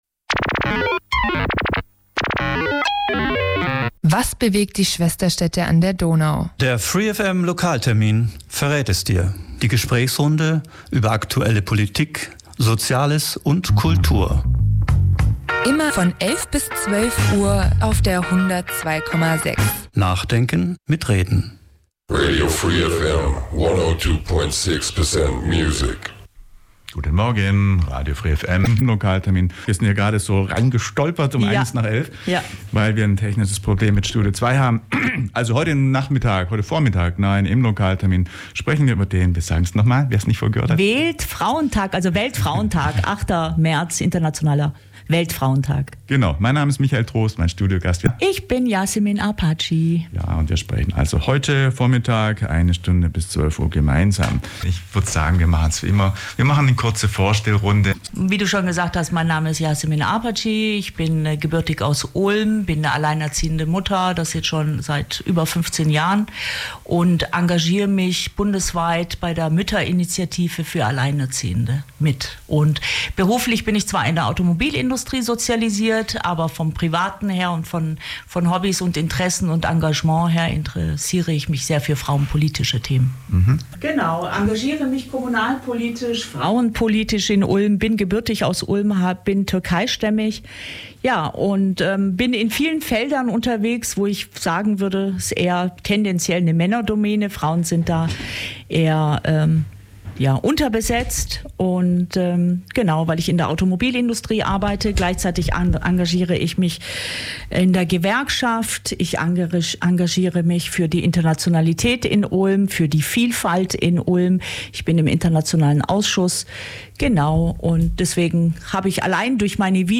Live-Text-Ton-Collage / 27.06. / 20:00 - 22:00 / "Tagebuch einer Invasion"
Eine Live-Text-Ton-Collage nach Andrej KurkowIm Anschluss der Podiumsdiskussion findet nach einer kurzen Umbaupause ab 20:00 Uhr in der Ulmer Volkshochschule eine Lesung statt. Die Redaktionen Freunde reden Tacheles und Schlecktronik gestalten eine Live-Text-Ton-Collage auf der Grundlage von Andrej Kurkows „Tagebuch einer Invasion“.